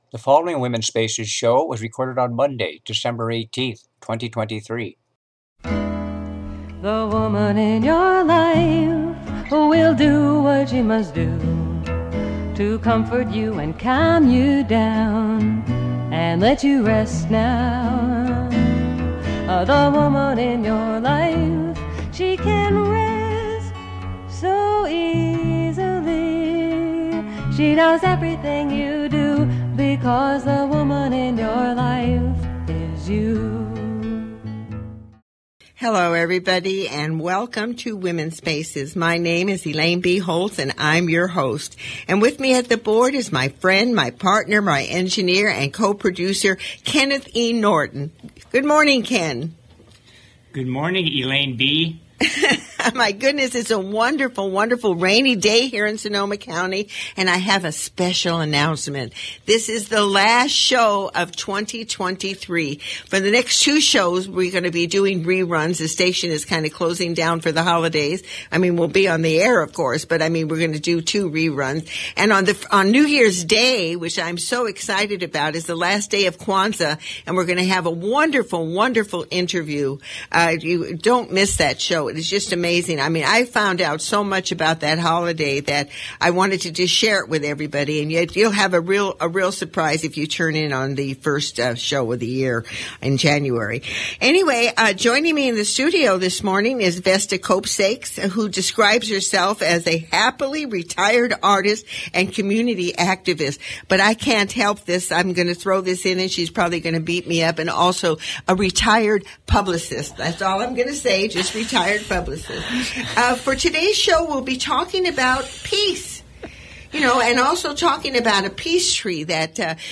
Download the show Duration 57 minutes The Guest interview begins at 19 minutes.